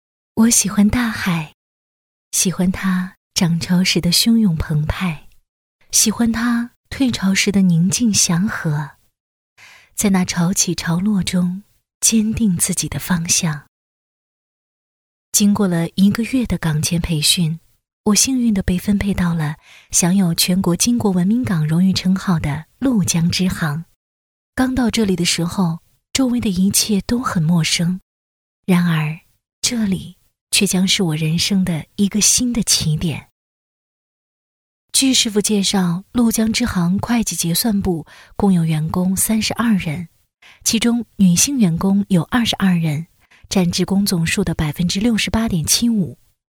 女16-内心独白【自然 抒情】
女16-质感知性 温柔知性
女16-内心独白【自然 抒情】.mp3